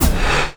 WHOOSH_Breath_mono.wav